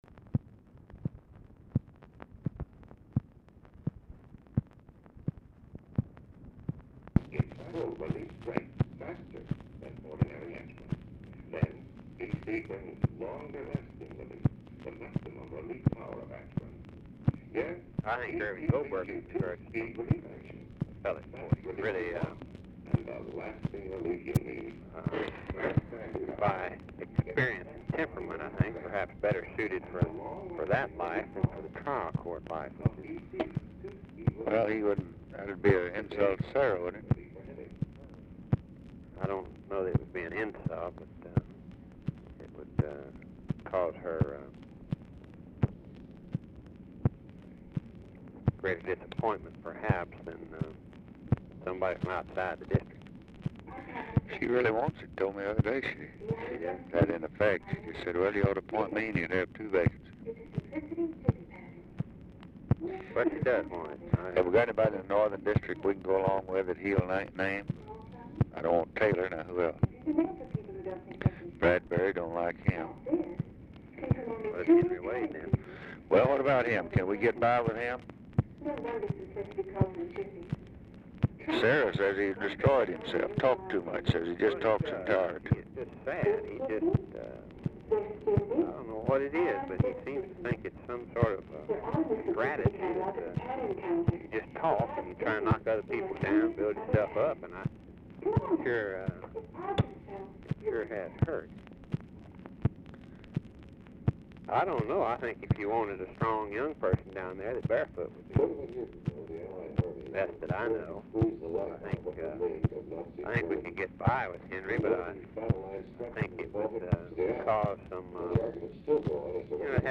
"TODAY" SHOW AUDIBLE IN BACKGROUND; CLARK IS DIFFICULT TO HEAR
Format Dictation belt
Location Of Speaker 1 Mansion, White House, Washington, DC
Specific Item Type Telephone conversation